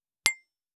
279,皿が当たる音,皿の音,台所音,皿を重ねる,カチャ,ガチャン,カタッ,コトン,
コップ効果音厨房/台所/レストラン/kitchen食器